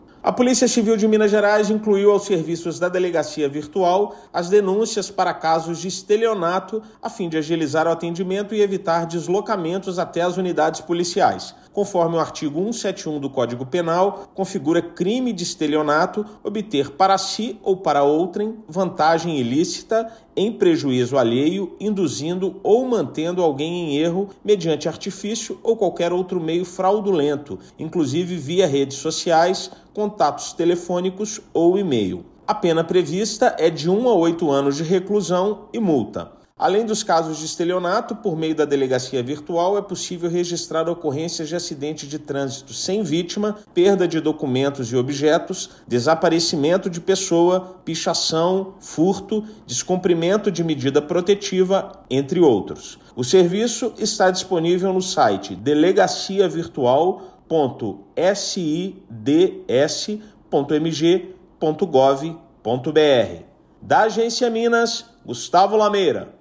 Nova opção permite denúncias de golpes praticados de forma presencial e eletrônica. Ouça matéria de rádio.